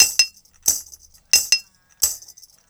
89-PERC1.wav